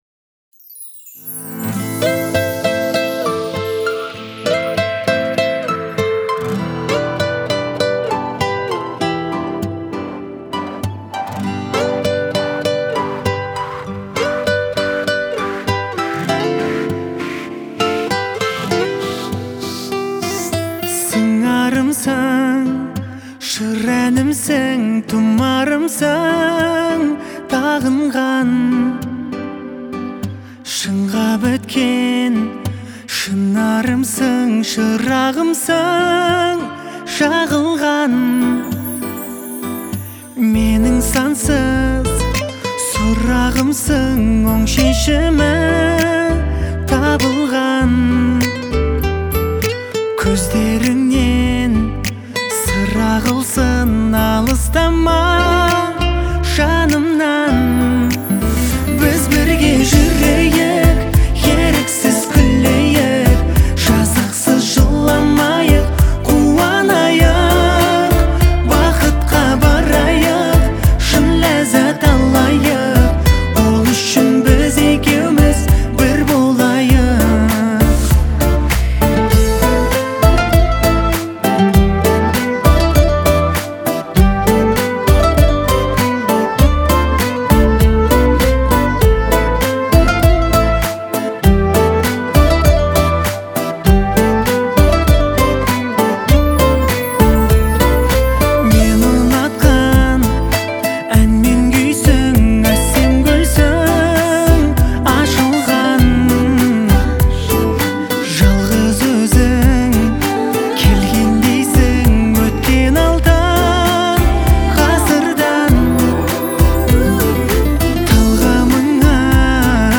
это яркая и мелодичная песня в жанре казахского попа